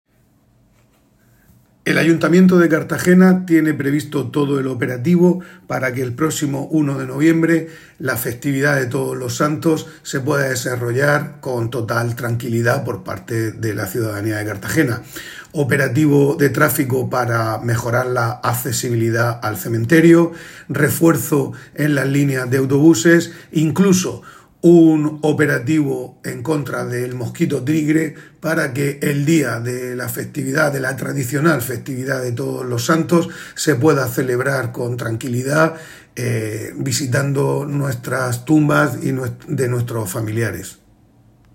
Enlace a Declaraciones del concejal responsable de los camposantos, Gonzalo López.